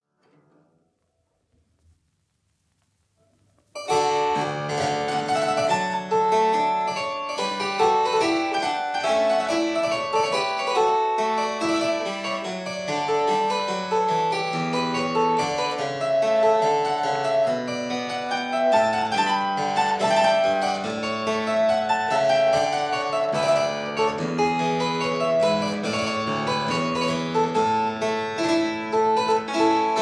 two manual harpsichord
(I: 16,8,4 leather; II: 8 quill, 8 lute)